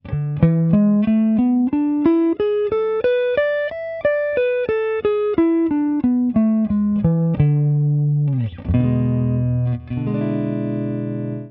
La pentatonique mixolydienne est une gamme pentatonique issue du mode Mixolydien.
La gamme pentatonique mixolydienne T M3 P4 P5 b7
Gamme guitare Jerrock
Penta_mixo_5e_position.wav